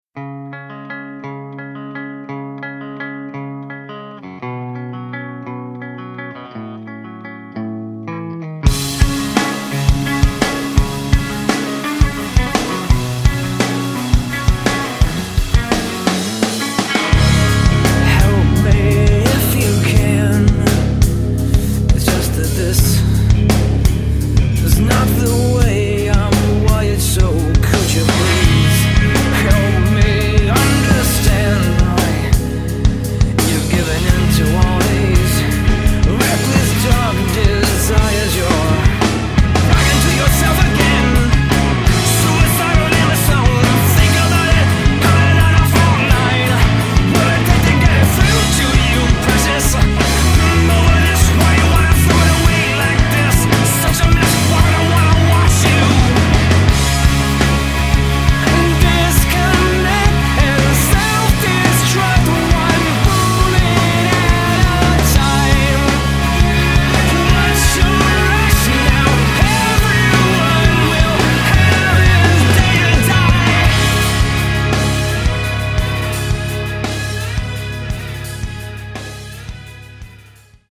bass guitar